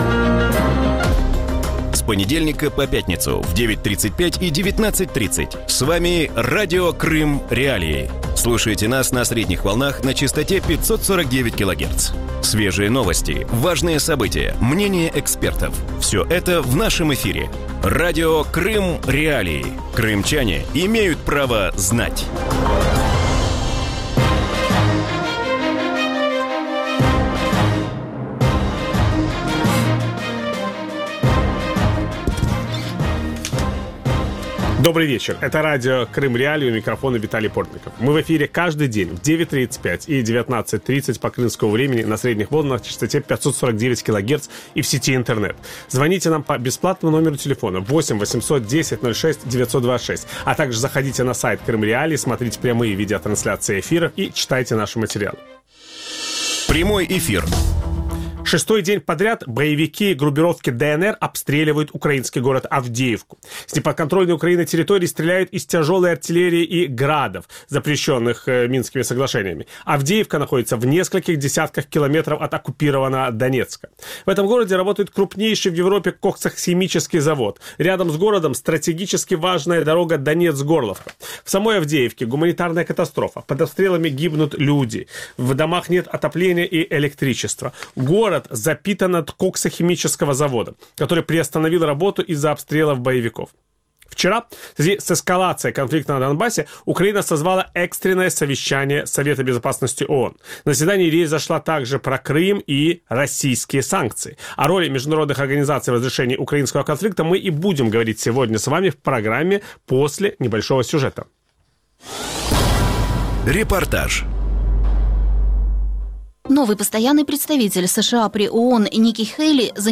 В вечернем эфире Радио Крым.Реалии говорят о последнем заседании Совета безопасности ООН, созванном Украиной в ответ на эскалацию в районе Авдеевки. Может ли Организация объединенных наций предложить действенные инструменты для противодействия российской агрессии и какая судьба ожидает ООН с новой администрацией президента США? На эти вопросы ответят экс-министр иностранных дел Украины Владимир Огрызко и российский политолог Дмитрий Орешкин. Ведущий программы – Виталий Портников.